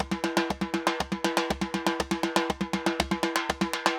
Timba_Candombe 120_2.wav